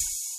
Southside Open Hatz (16).wav